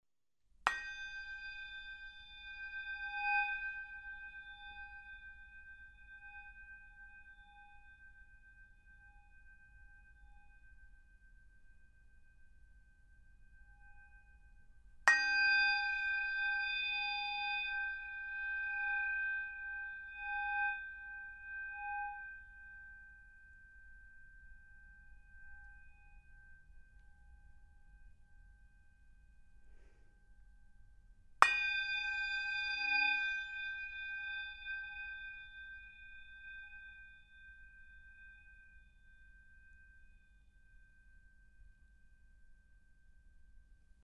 Il consiglio è sempre lo stesso: ASCOLTATE SOLO CON LE CUFFIE altrimenti non potrete cogliere gli effetti spaziali
Bicchieri
bicchieri.mp3